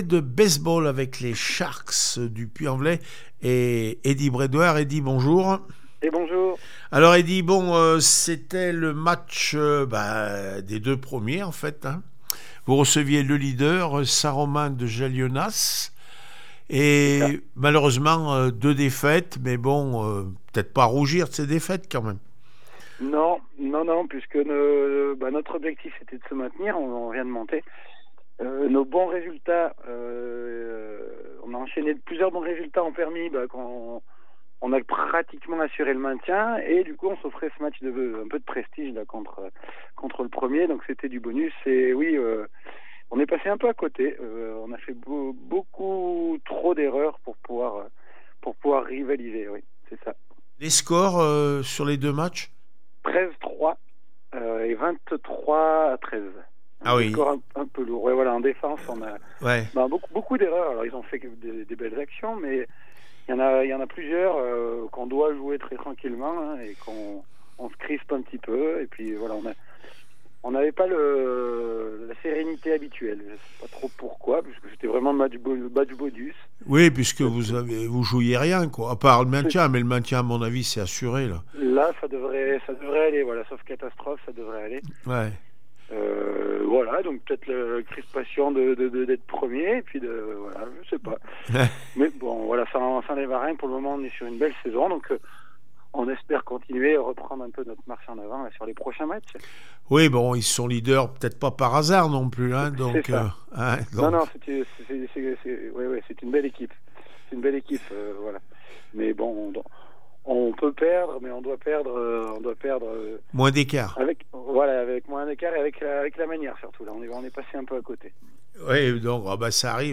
base ball les sharks du puy 0-2 st romain de jalonnas bats réaction après match